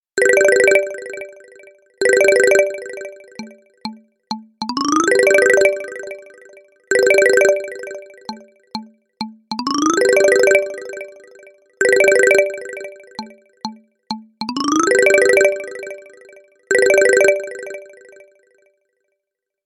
• Песня: Рингтон, нарезка
• Категория: Красивые мелодии и рингтоны